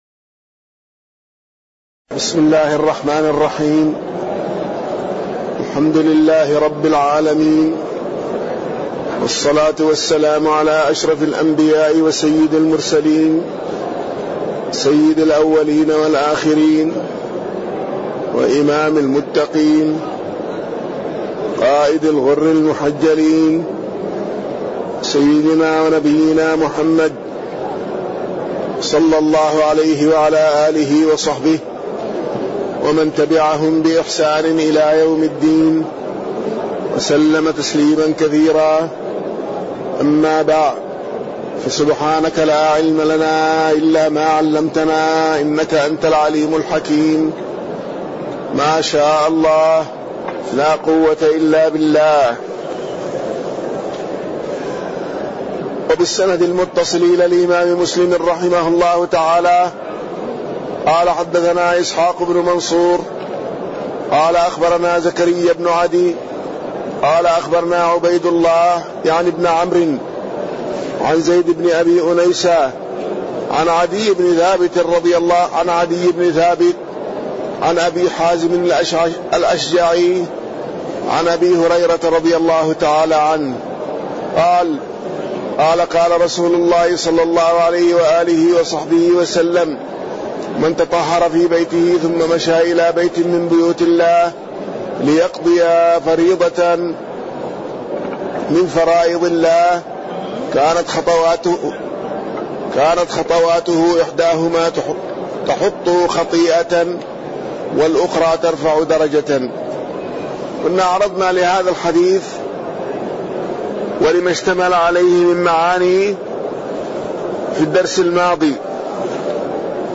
تاريخ النشر ٣ ربيع الثاني ١٤٣٠ هـ المكان: المسجد النبوي الشيخ